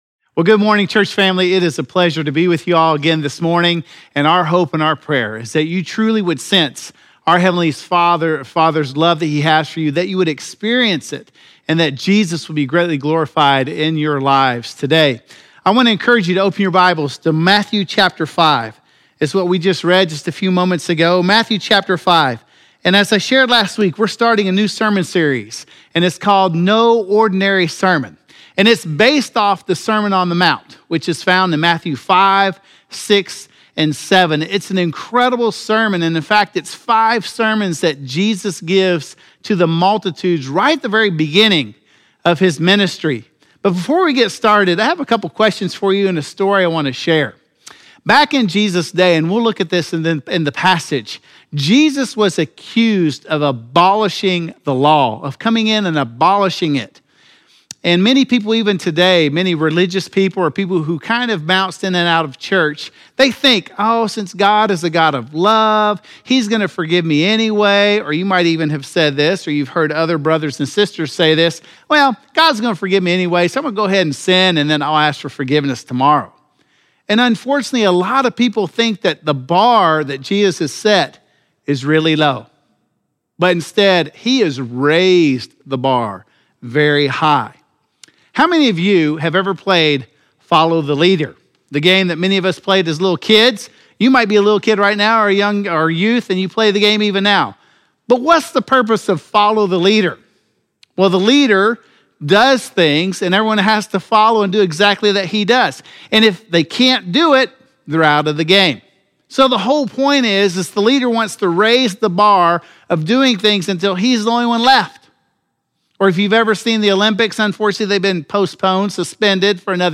Woodbine_Sermon_4-26.mp3